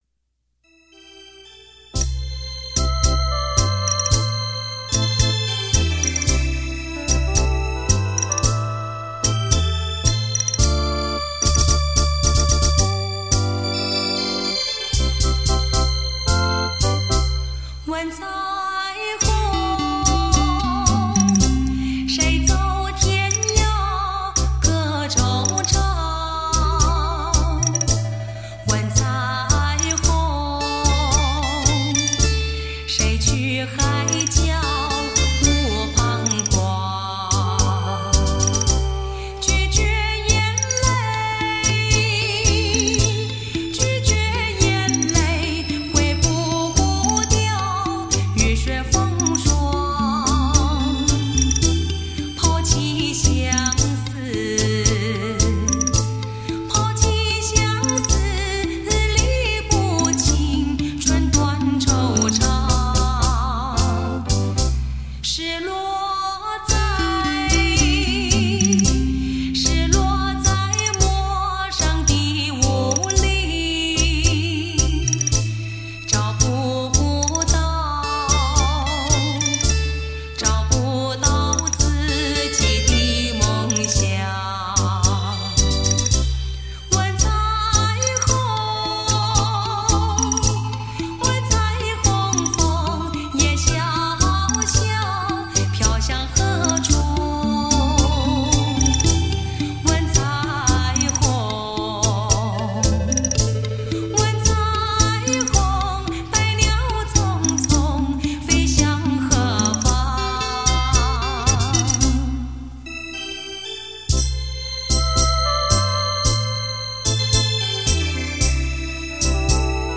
国语怀念流行金曲